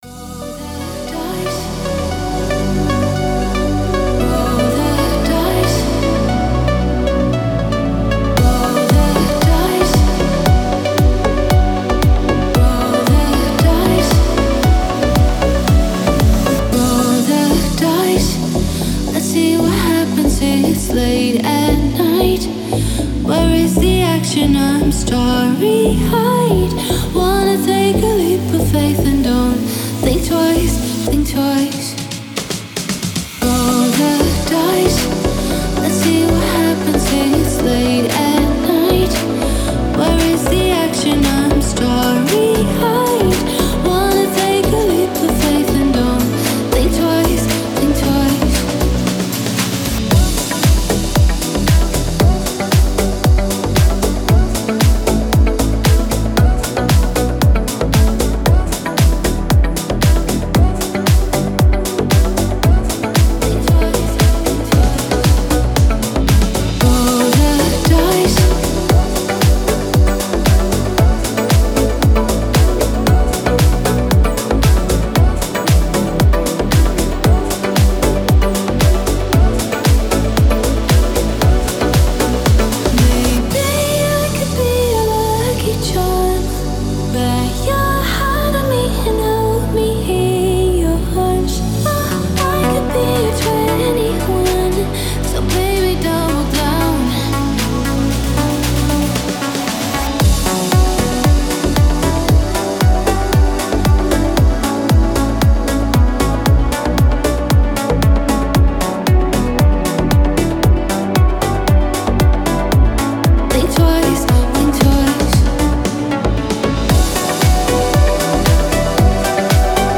Deep House музыка